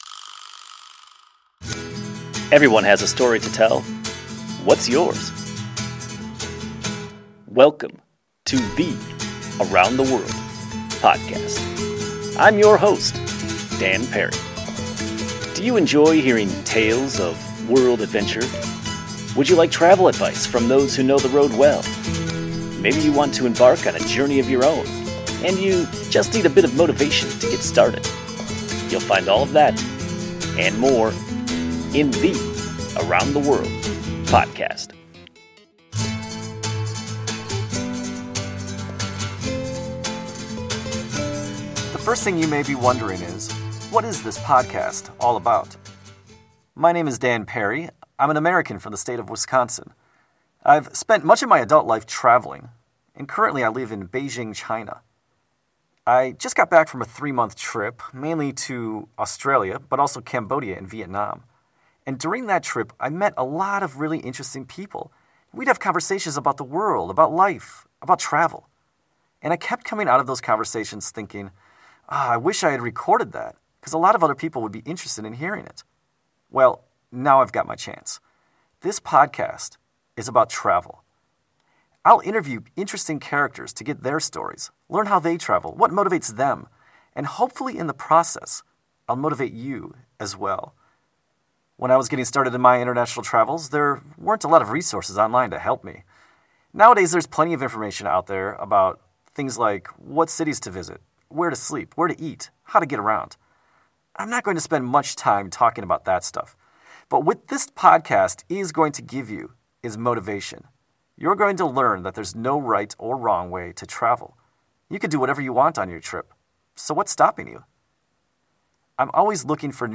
The Around the World Podcast is an hour-long show that focuses on travel. I'll interview interesting characters to learn how they travel and what motivates them.